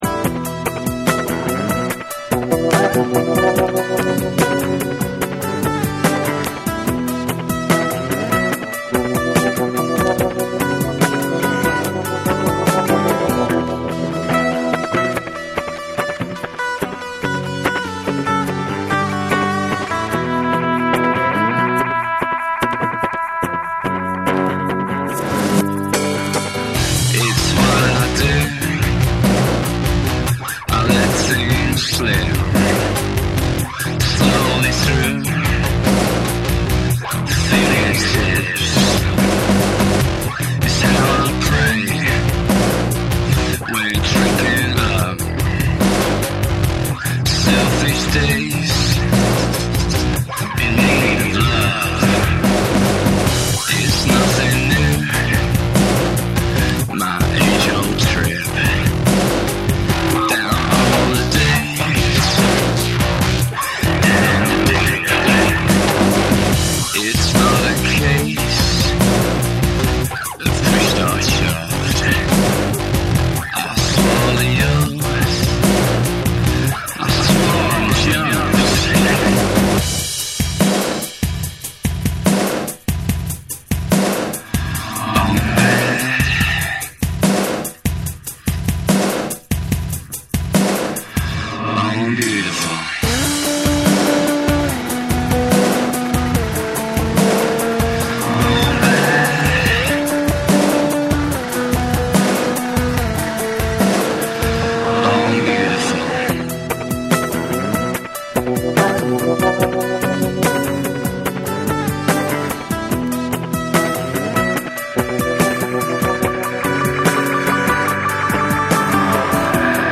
BREAKBEATS / NEW WAVE & ROCK